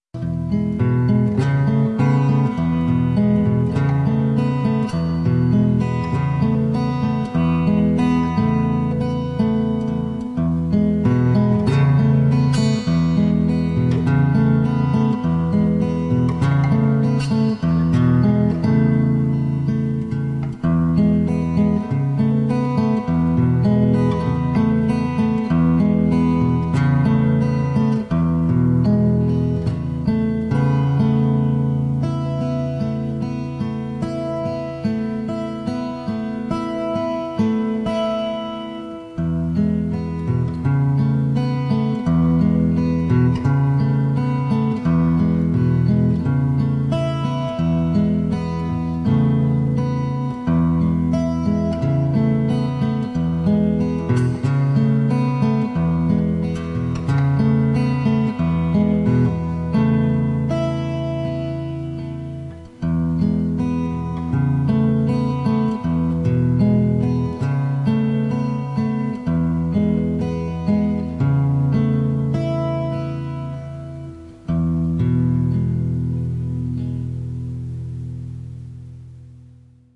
Basically, a warm, very gentle piece of guitar playing.